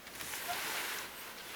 ylitse lentää laulujoutsen?